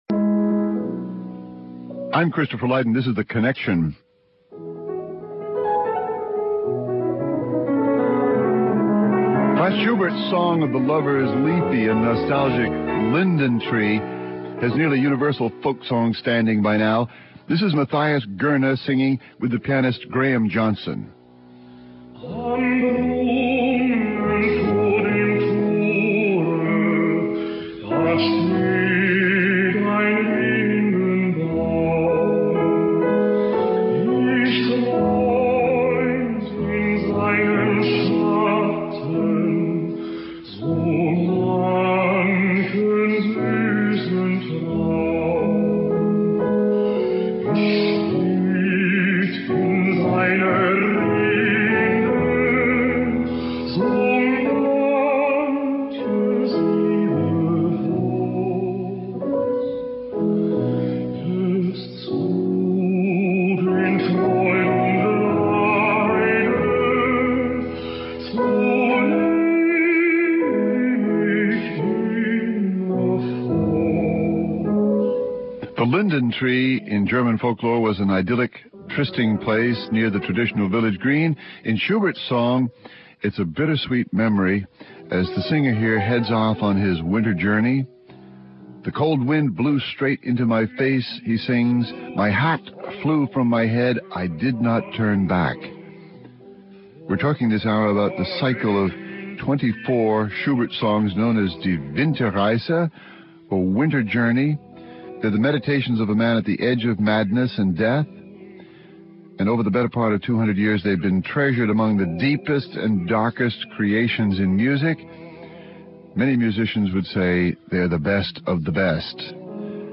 Pianist
baritone